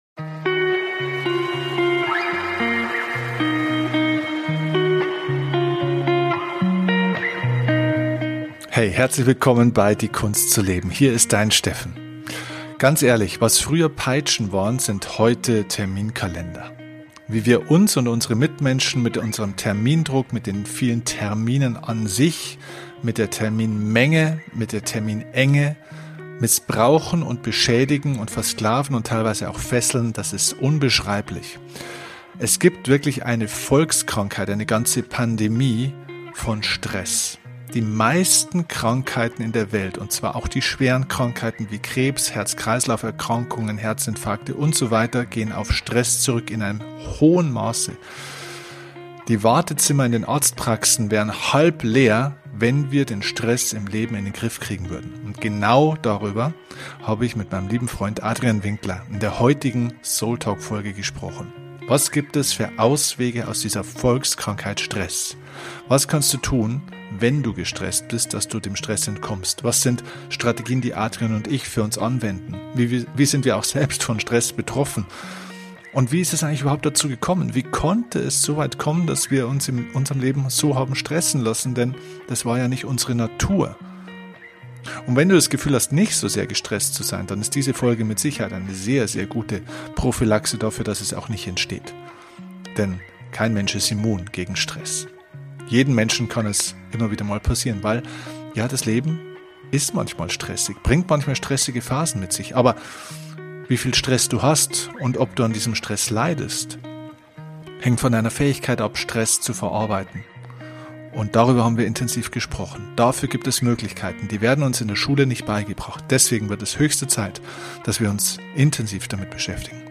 Wir sprechen über die psychologischen Aspekte des Stresses und die Notwendigkeit bewusster Pausen. Wie immer bei diesem Format: kein Skript, nur zwei Freunde die miteinander reden.